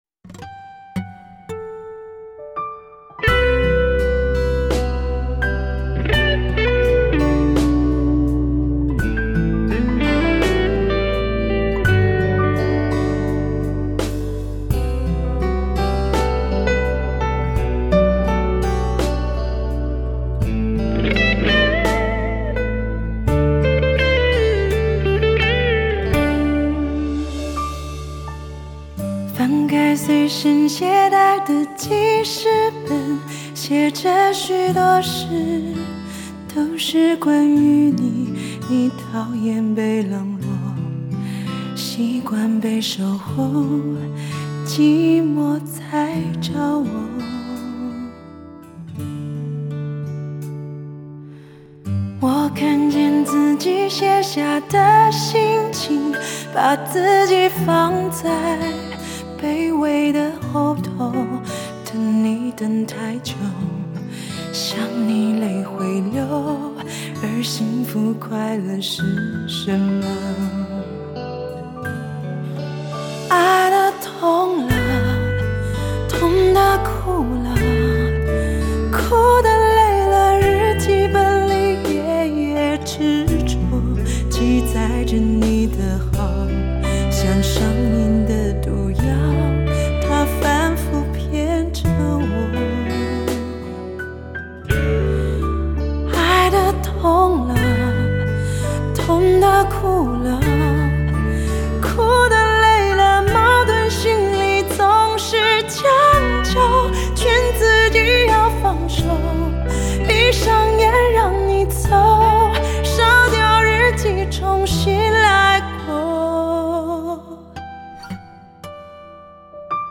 自由奔放的吟唱
而是她能够勇敢把自己的情绪自然不掩饰的表现在歌里。
那弦乐营造出凄美感性的音乐风格，那自由奔放的吟唱，
由顶级录音完全刻画出来，其低频震撼，音场及乐器的层次分明，